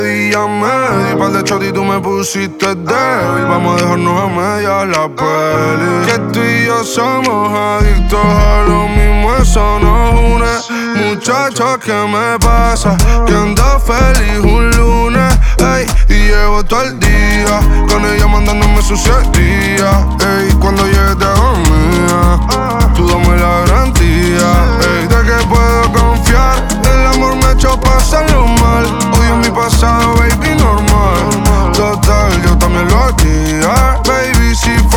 Latin Urbano latino
Жанр: Латино